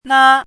chinese-voice - 汉字语音库
na1.mp3